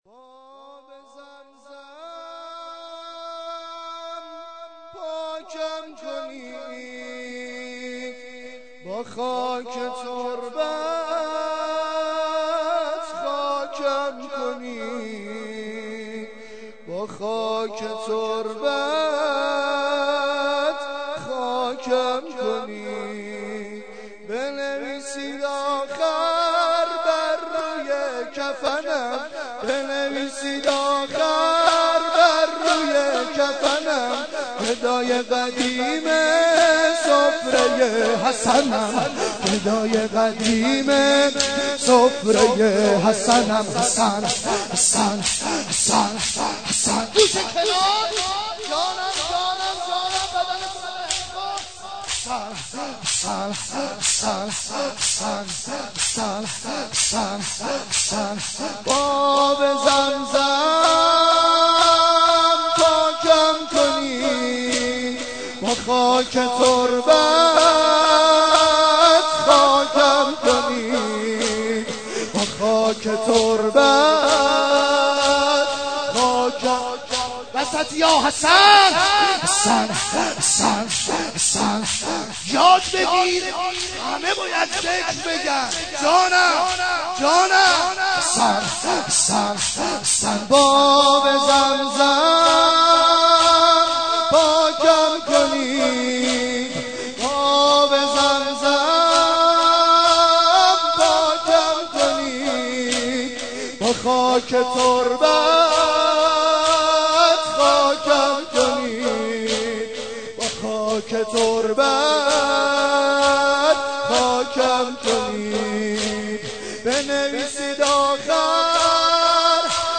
عقیق: مراسم مناجات در شب های ماه مبارک رمضان با مناجات خوانی حاج محمدرضا طاهری برگزار شد.
برای دیدن مکان برگزاری دیگر مناجات ها کلیک کنید بخش اول - مناجات بخش دوم - روضه بخش سوم - سینه زنی